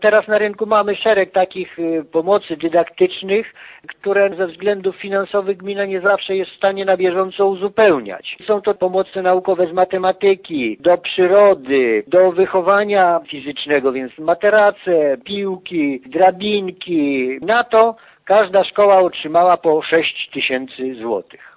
Wszystkie szkoły podstawowe przystąpiły do programu „Radosna szkoła” i każda z 5 placówek otrzymała pieniądze na nowe pomoce naukowe – informuje wójt Franciszek Kwiecień: